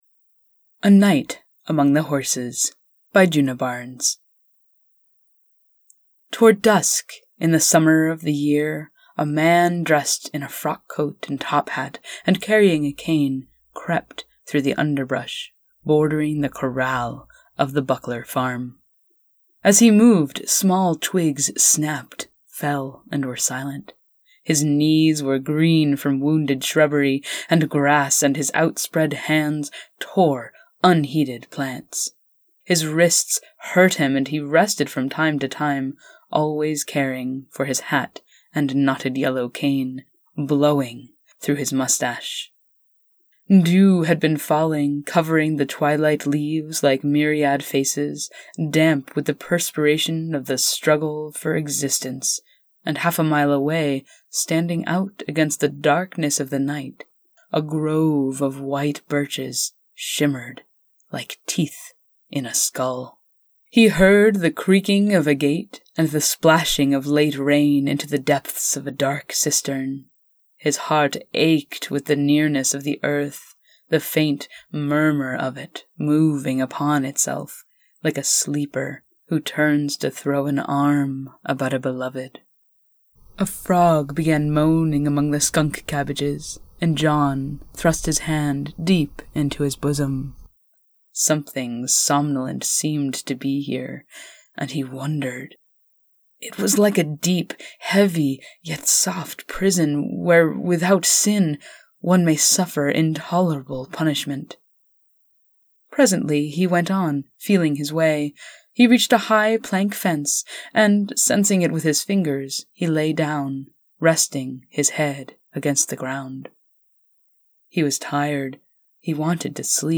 Narrated Version of Djuna Barnes’ “A Night Among the Horses” | ePublish Yourself!